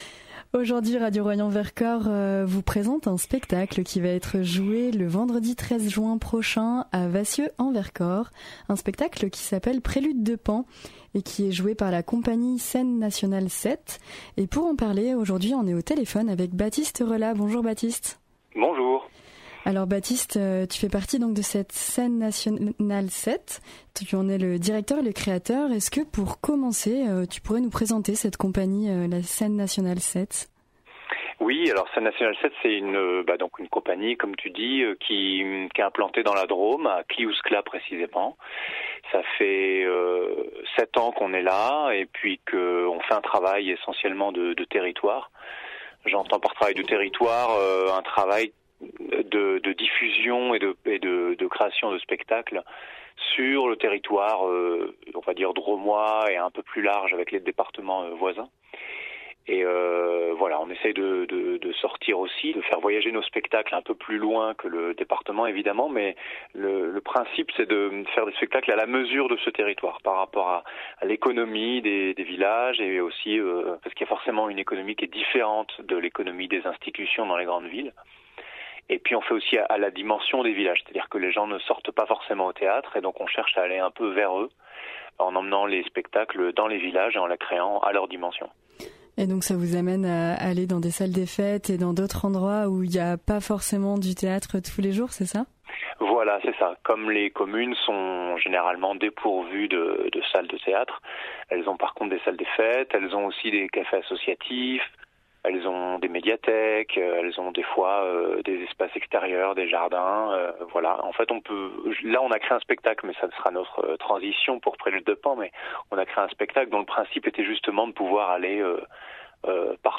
Radio Royans Vercors a interviewé